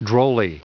Prononciation du mot drolly en anglais (fichier audio)
Prononciation du mot : drolly